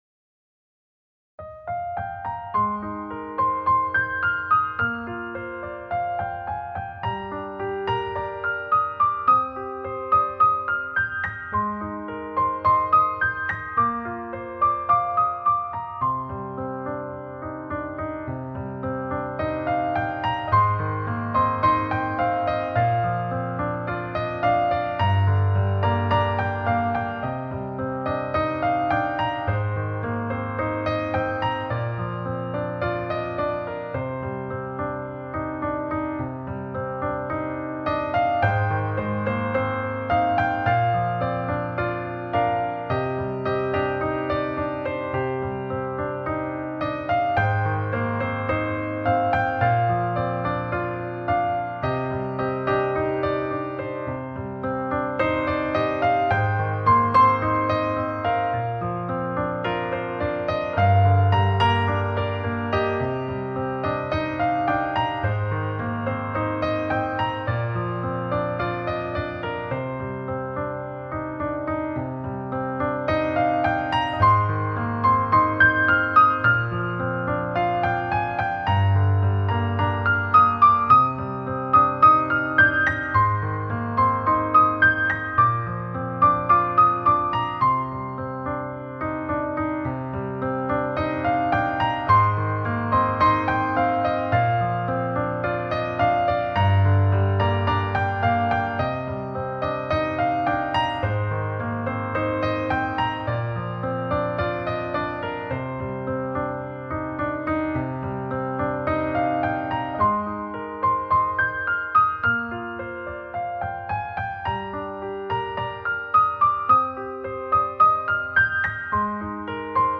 作品轻盈舒畅
钢琴曲